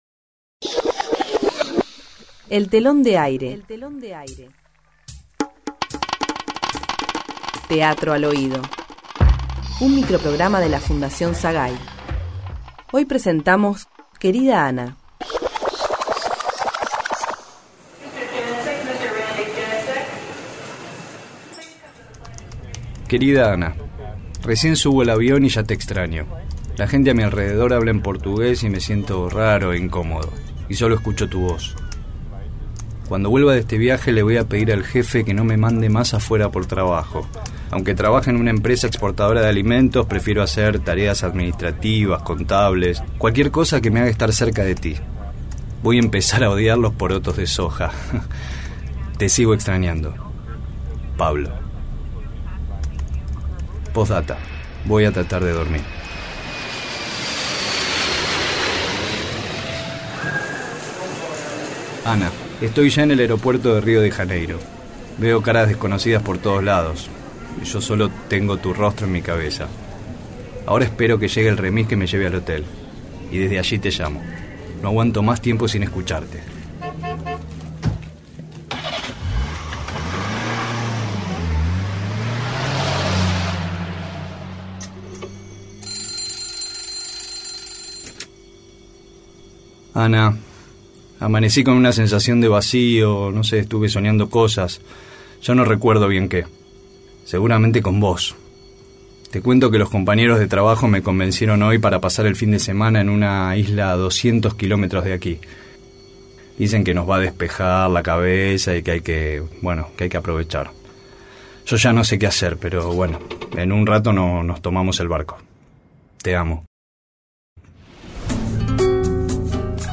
Título: Querida Ana… Género: Ficción. Sinopsis: Un hombre, en viaje de trabajo, escribe reiteradas cartas a su enamorada. Pero el paisaje y la música de una isla paradisíaca esconden una posdata final.
grabación en estudio